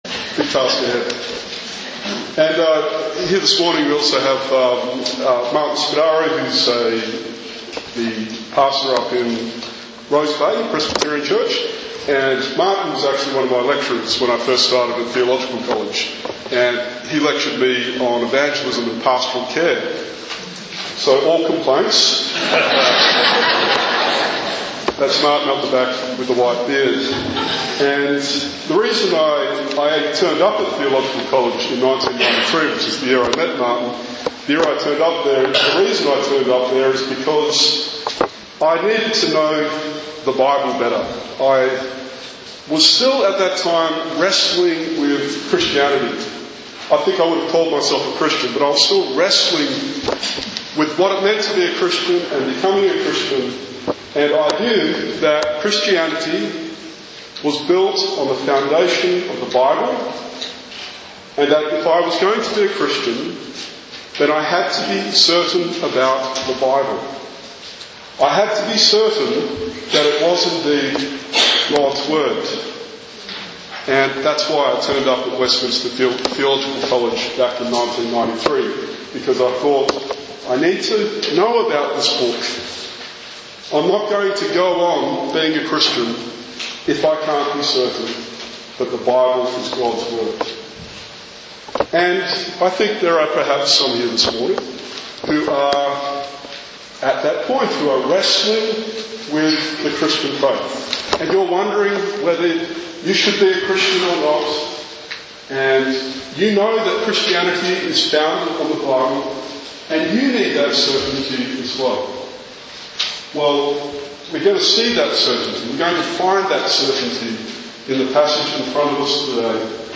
Today is the third and final sermon in a miniseries of three on the Bible. 2 Peter 1 gives a remarkably clear picture of how the Bible came to be.